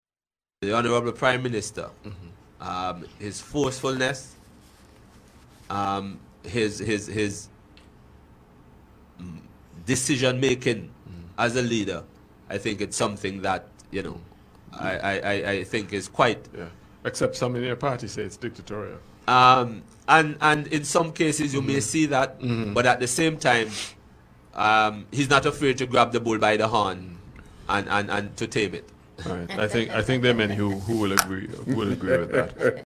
During an interview with Twin Island Media, Jamale Pringle, the Leader of the Opposition in Antigua, expressed his views on the Prime Minister, Gaston Browne.